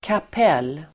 Uttal: [kap'el:]